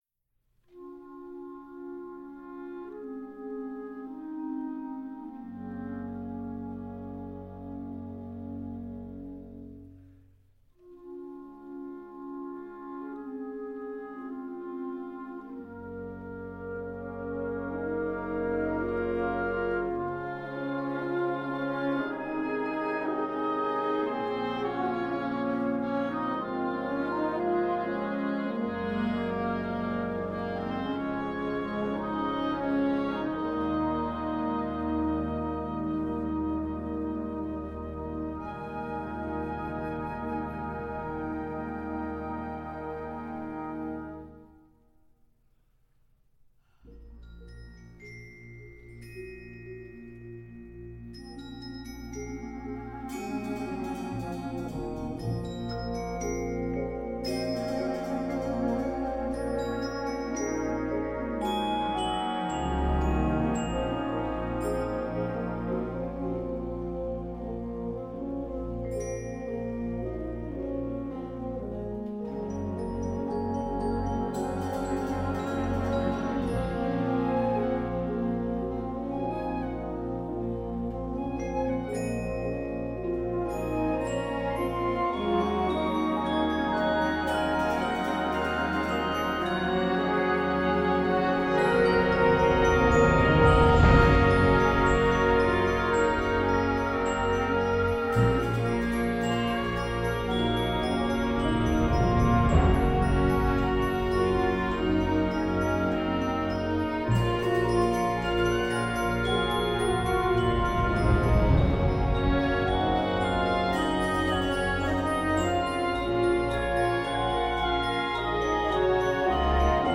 Gattung: Jugendwerk
5:00 Minuten Besetzung: Blasorchester PDF